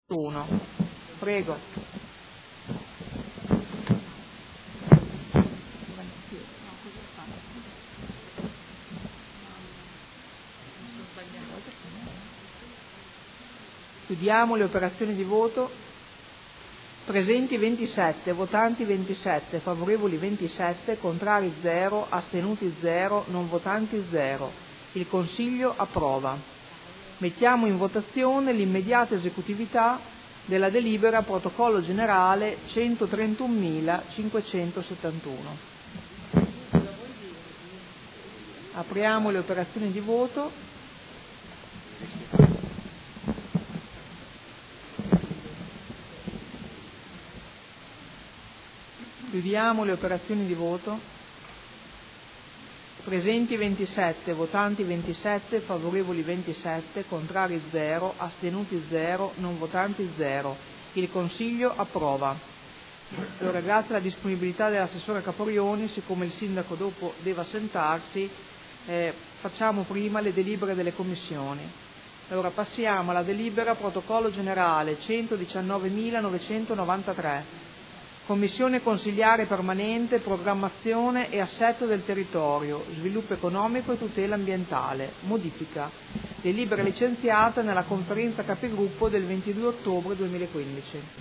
Seduta del 22 ottobre. Proposta di deliberazione: Convenzione tra il Comune di Modena e la Provincia di Modena per il servizio in forma associata delle funzioni di Segretario.